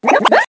One of Koopa Troopa's voice clips in Mario Kart Wii